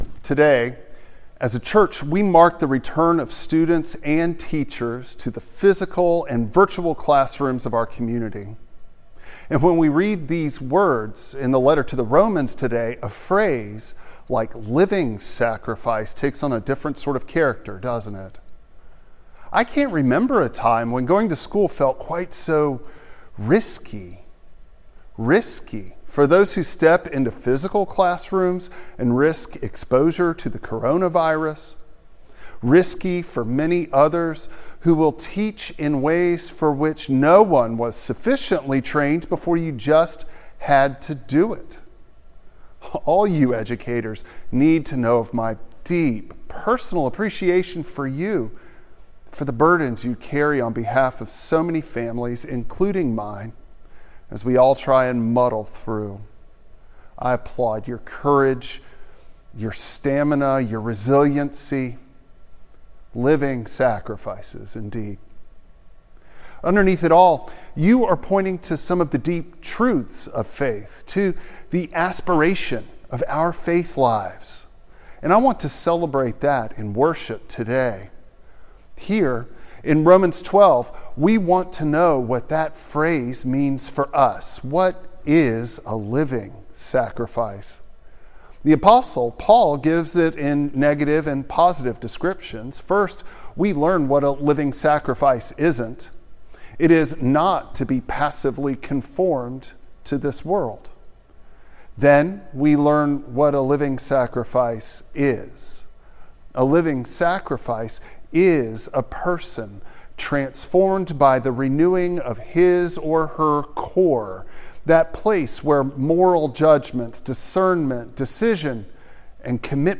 Preacher: